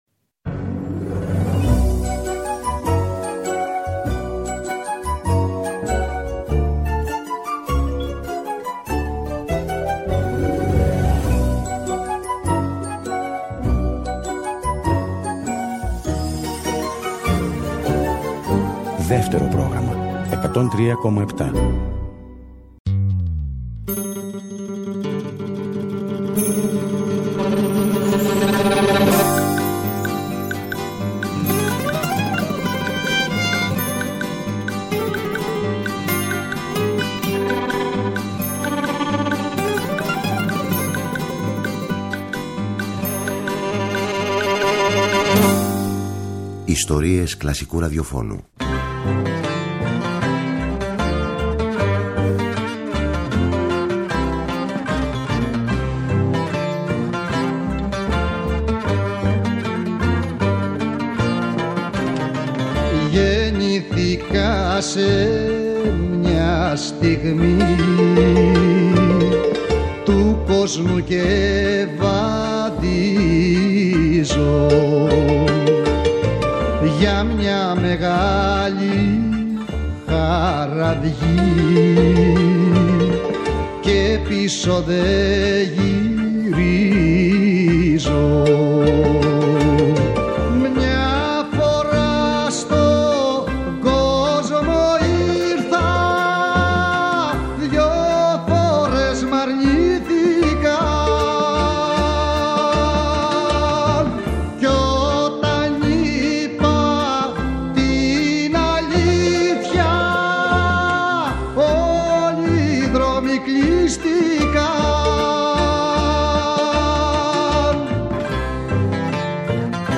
Θα μου πείτε αυτή η δεύτερη εκπομπή καλύπτει όλο το έργο του; Όχι φυσικά, μας δίνει όμως την ευκαιρία να καλύψουμε κενά, να φωτίσουμε συνεργασίες ξεχασμένες, να ακούσουμε τραγούδια του που σπάνια ακούγονται, να θυμηθούμε μεγάλες επιτυχίες, αλλά να ακούσουμε και τον ίδιο να απαγγέλει!